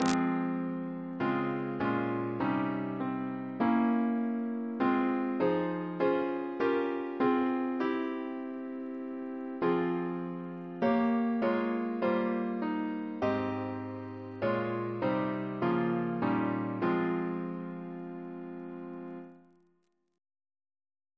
CCP: Chant sampler
Double chant in F minor Composer: Alec Wyton (1921-2007), Organist of St. John the Divine Reference psalters: ACP: 47